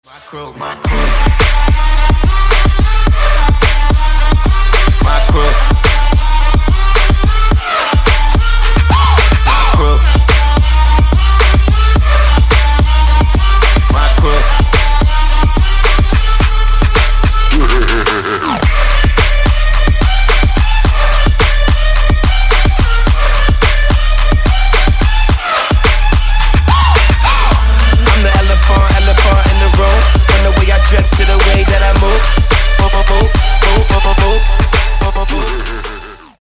• Качество: 224, Stereo
Хип-хоп
Trap
Rap
Bass